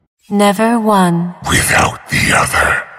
lol-voices-champions-selection-quotes-english-patch-5.mp3